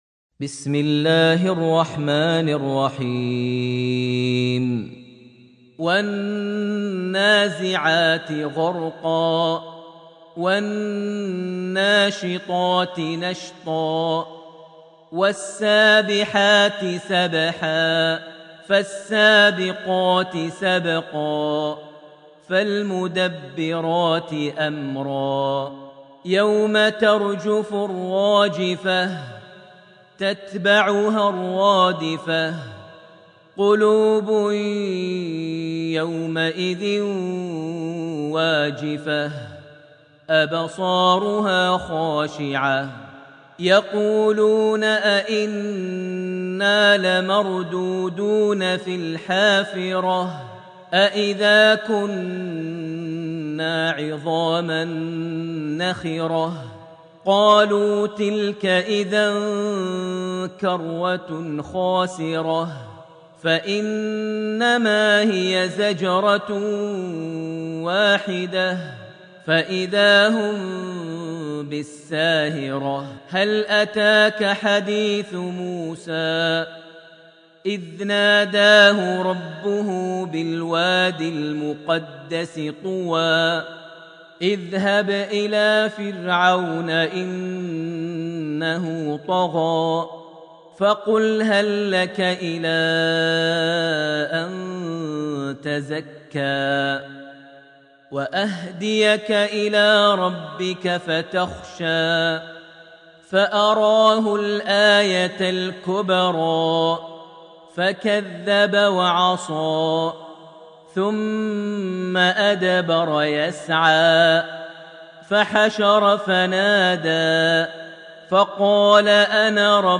Surat Al-Naza’at > Almushaf > Mushaf - Maher Almuaiqly Recitations